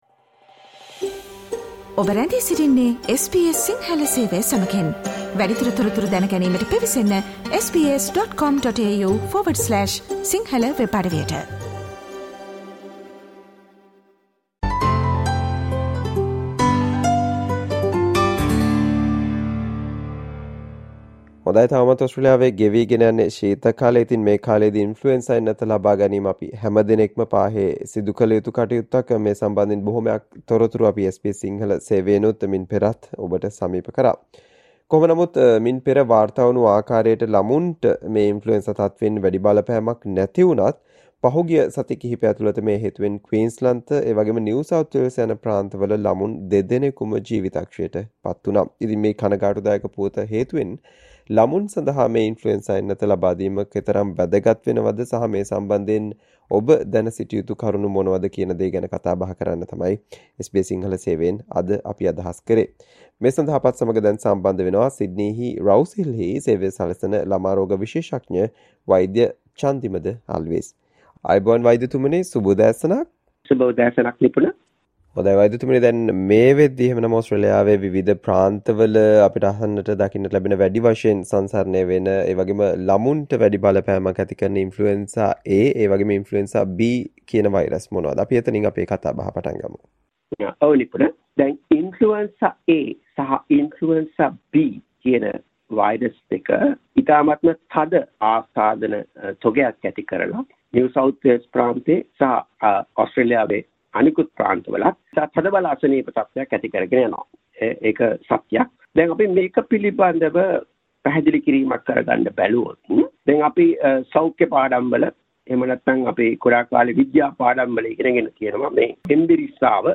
SBS Sinhala discussion on the things we should do to prevent children from getting the Influenza virus with the recent flu situation